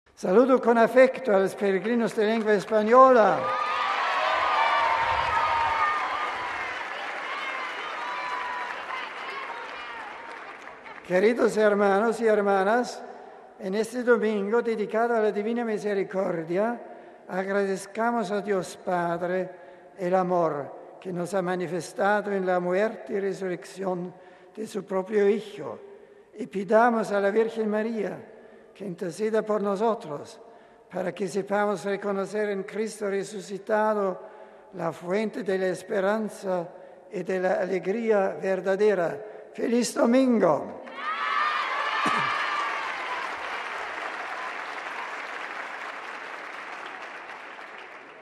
Domingo, 30 (RV).- El Santo Padre Benedicto XVI ha presidido, desde el balcón del Palacio Apostólico de Castelgandolfo donde ha transcurrido unos días de descanso, el rezo mariano del Regina Coeli en esta festividad de la Divina Misericordia, que como ha recordado el propio Pontífice fue instaurada durante el Jubileo del 2000 por el Siervo de Dios Juan Pablo II, coincidiendo con la canonización de Faustina Kowalska, humilde religiosa polaca.
Y en español, éstas han sido las palabras que Benedicto XVI ha dirigido a todos los fieles: RealAudio